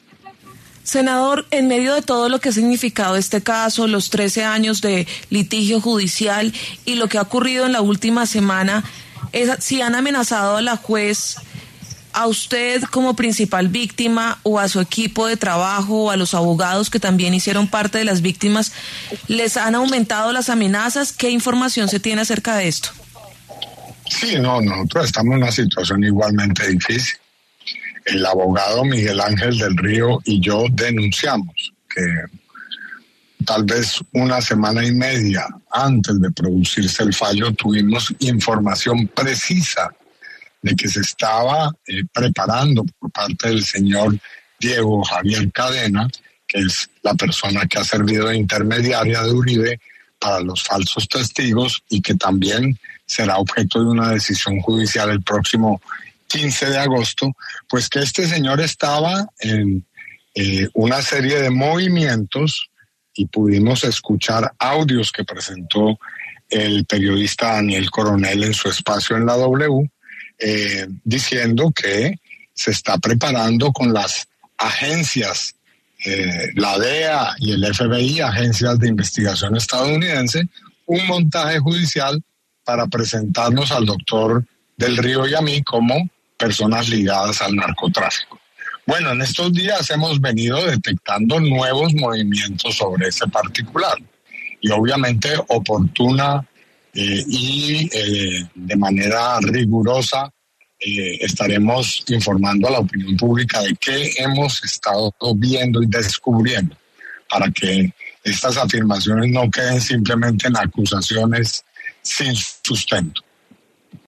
El senador Iván Cepeda, principal víctima en el caso contra el expresidente Álvaro Uribe Vélez, aseguró, en entrevista con W Radio, que ha recibido amenazas tras conocerse la condena de 12 años de prisión contra el también exsenador, por los delitos de soborno en actuación penal y fraude procesal.